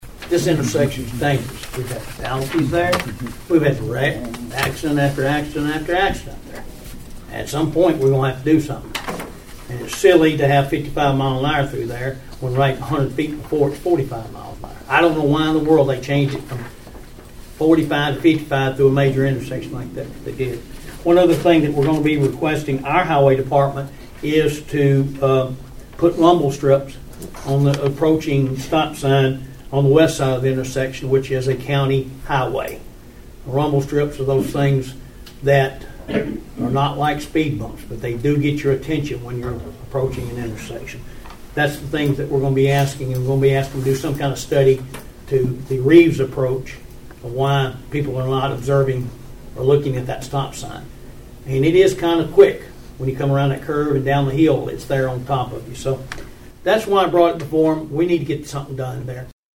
County Commissioner Danny Jowers talked about the requests for TDOT at the intersection of 45-West and State Route 216.(AUDIO)